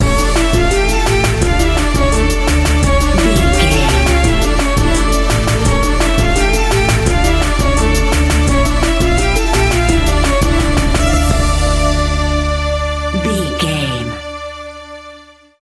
Aeolian/Minor
Slow
World Music
percussion